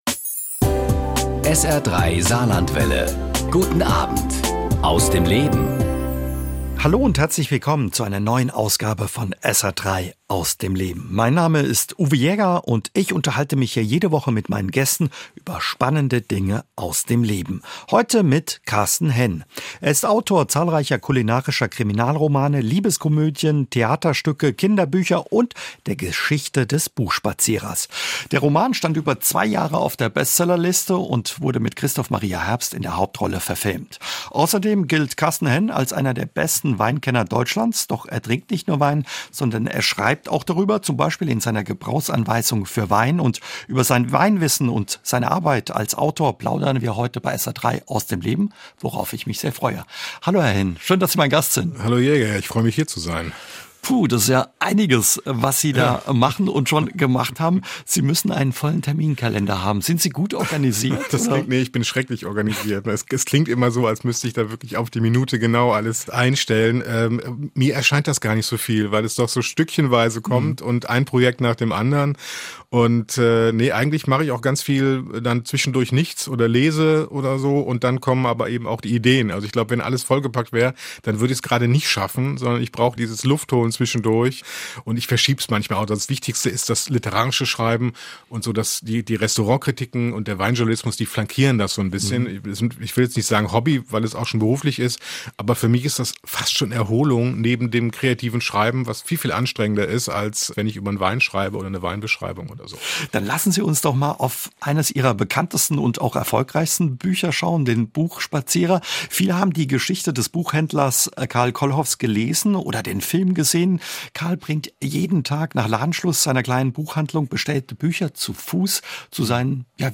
Jetzt ist druckfrisch sein neuer Roman "Sonnenaufgang Nr. 5" erschienen. Darüber, seinen Alltag als Autor und seine Weinleidenschaft plaudert er in SR 3 "Aus dem Leben".